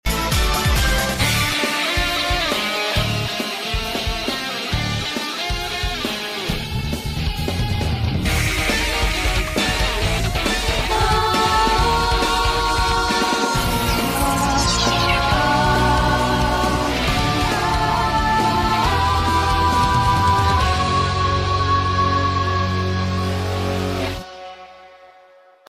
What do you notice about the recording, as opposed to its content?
(Demo Vercion)